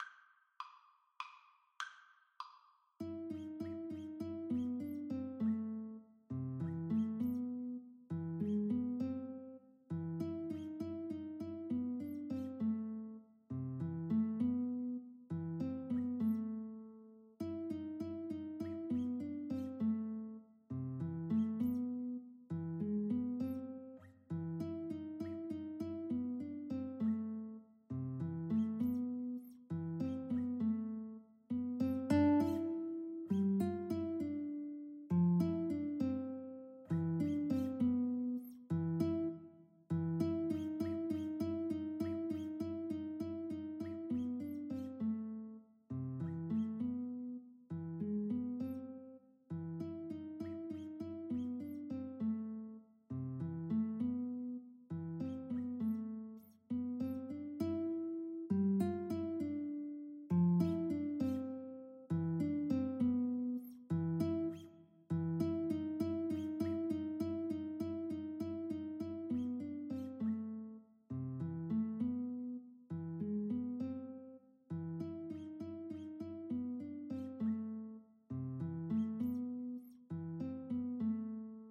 Free Sheet music for Guitar Duet
A minor (Sounding Pitch) (View more A minor Music for Guitar Duet )
3/4 (View more 3/4 Music)
=150 Moderato
Guitar Duet  (View more Easy Guitar Duet Music)
Classical (View more Classical Guitar Duet Music)